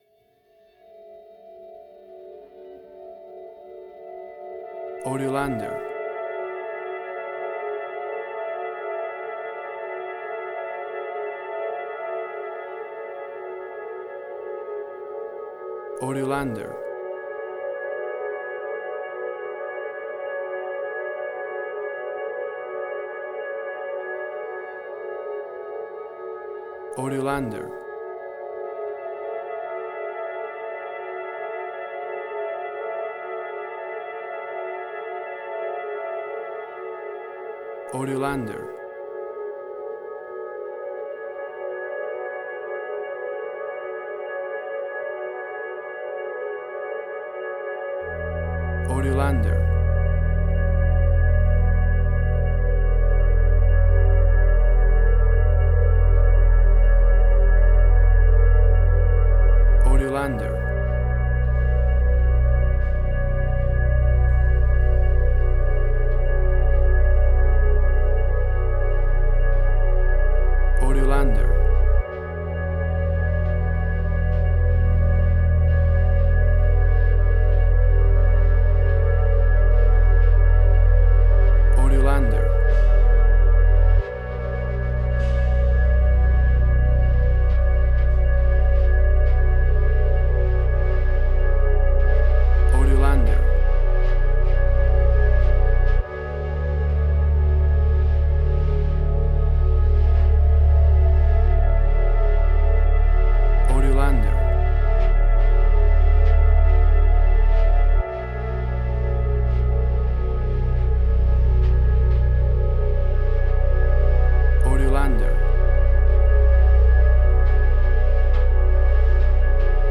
Modern Film Noir.